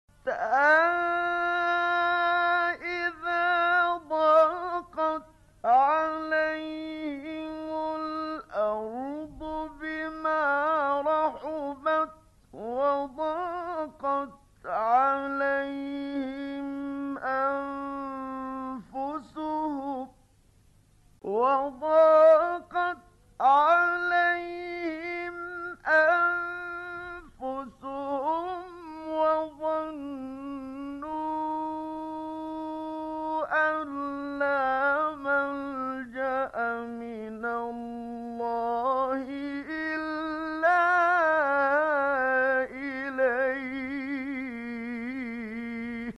ماتيسر من سورة(التوبة) بصوت الشيخ